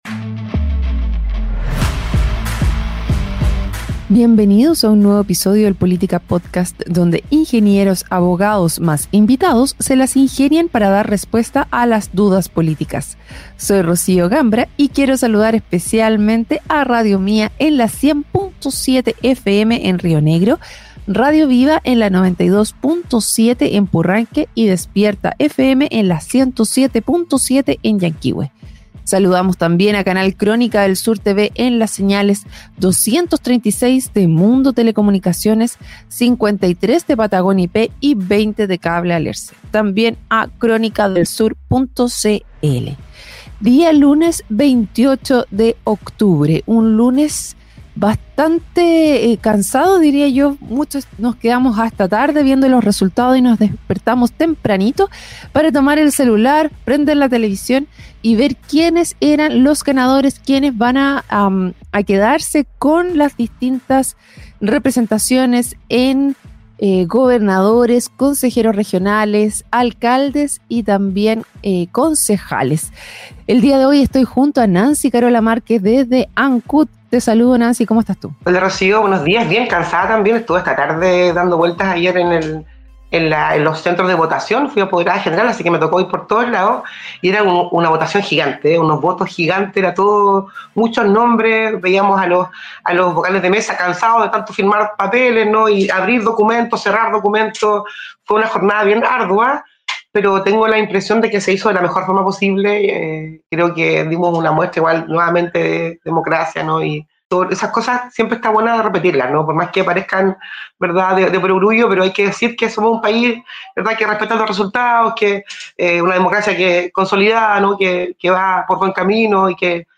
Durante el programa, las comentaristas compartieron sus observaciones sobre el ambiente electoral, los desafíos del voto obligatorio y el escenario político emergente en la región.